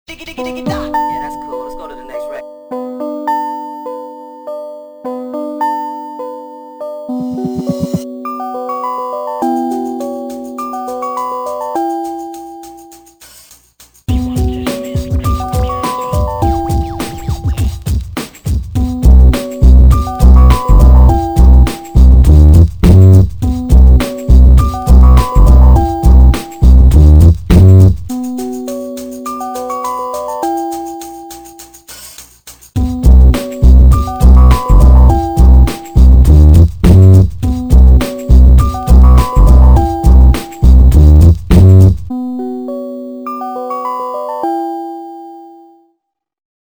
Качай ппц убойный Басс проверь свой СавБуфер на прочнось ! biggrin
400kg_-_bass.mp3